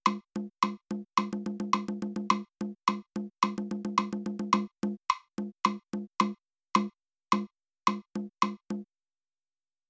Listen to the snare drum play in time to the beat.
Did you hear how the snare drum is right on the beat? It does not rush or drag the figure.
Rhythmic-Accuracy-1-Drum-EX1-GOOD.mp3